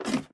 step / ladder1